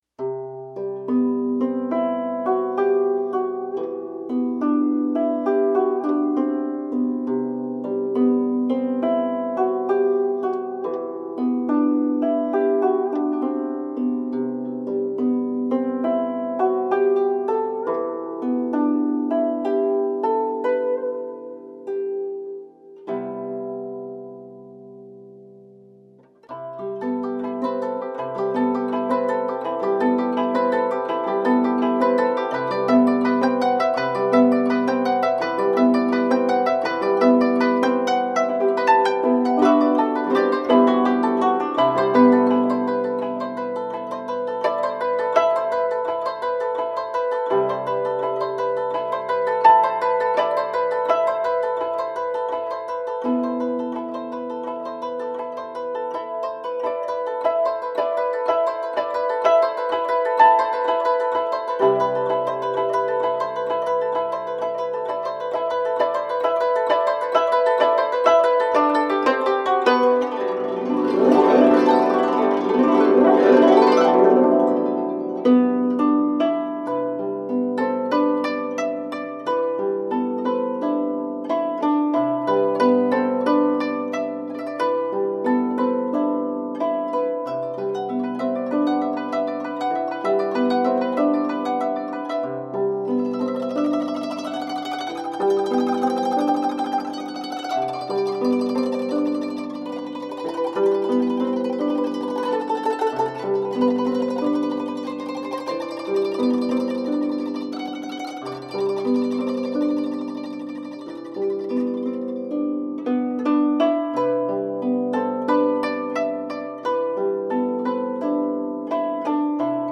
Melodic and mixed world tunes on japanese koto.
Tagged as: World, Other, Hammered Dulcimer, World Influenced